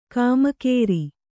♪ kāmakēri